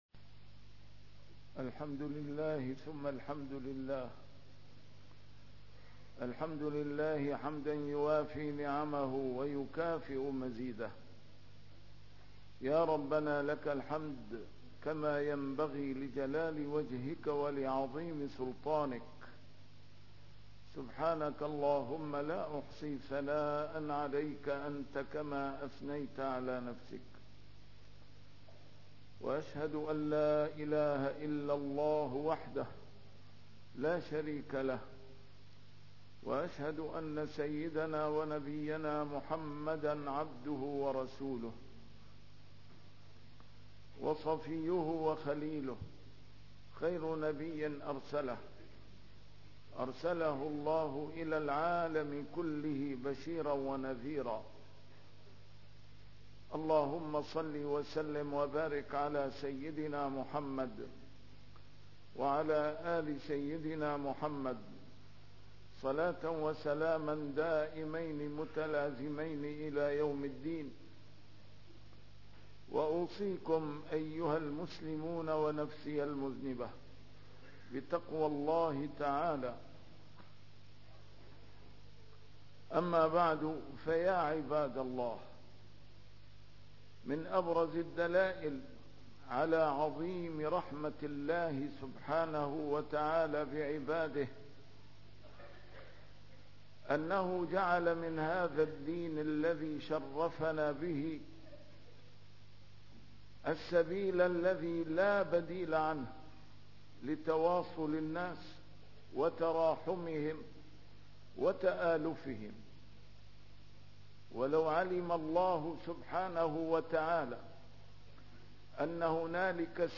A MARTYR SCHOLAR: IMAM MUHAMMAD SAEED RAMADAN AL-BOUTI - الخطب - شجرة الدين كلها شُرِعت غذاء لشبكة التراحم بين عباد الله